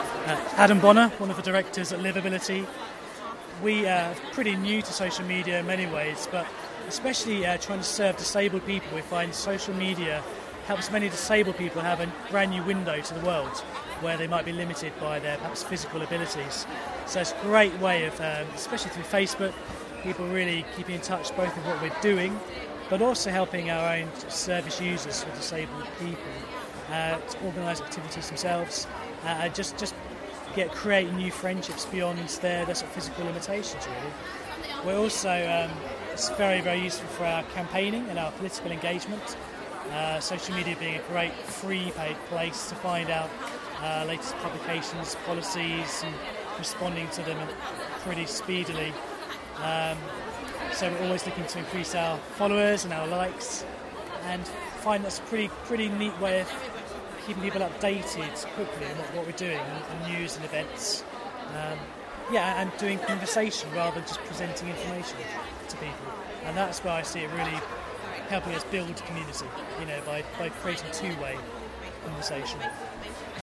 Collected at Greenbelt 2013, we had a quick chat